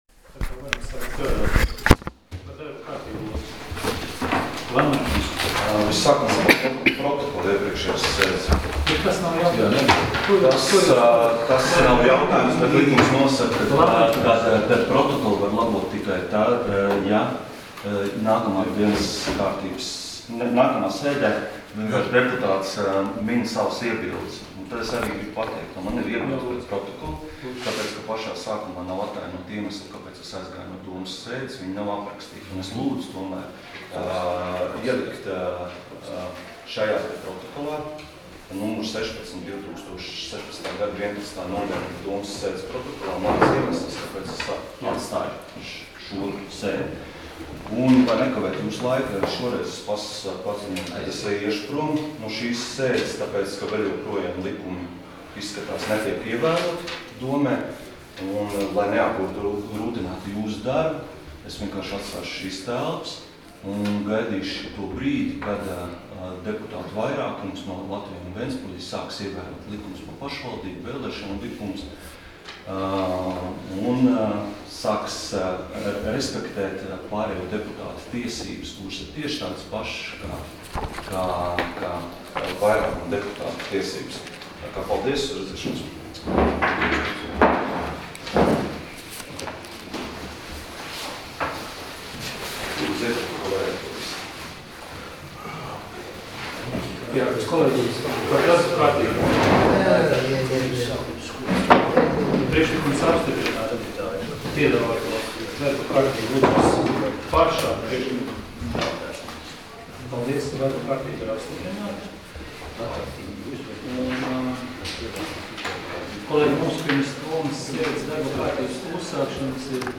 Domes sēdes 09.12.2016. audioieraksts